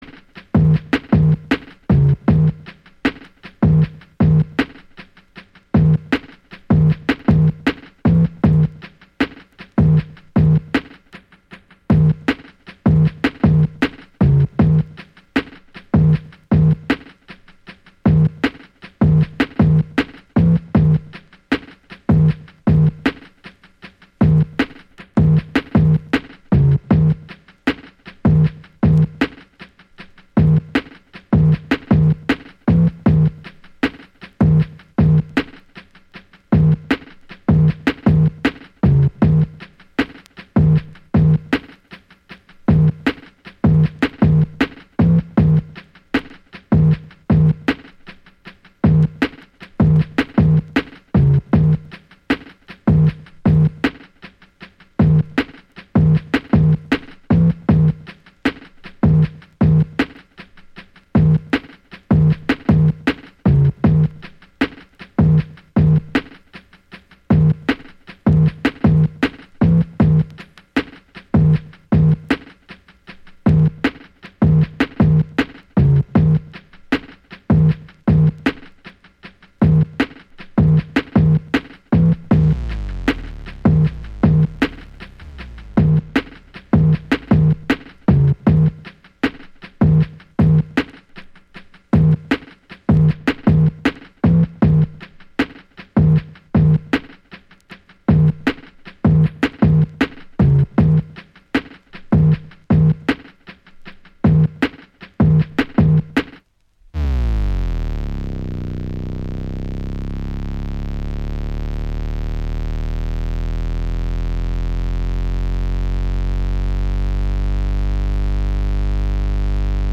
Genre: Hip Hop
Style: DJ Battle Tool